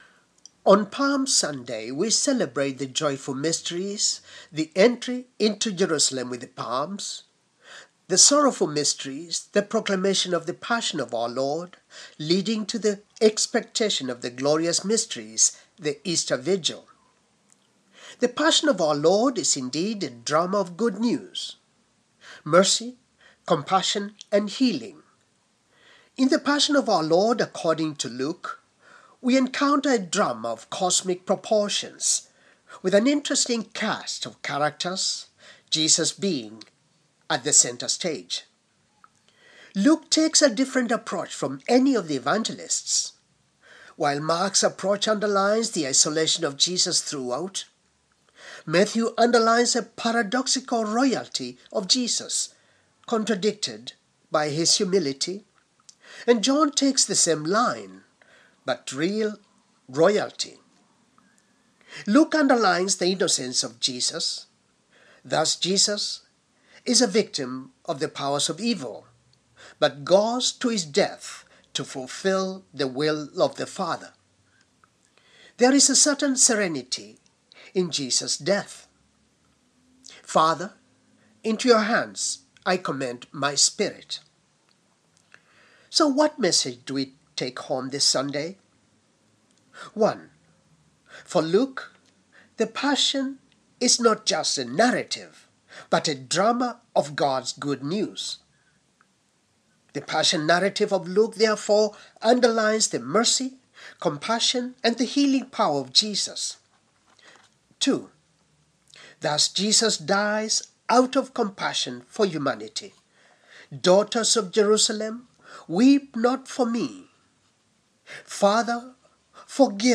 Homily for Palm Sunday 2013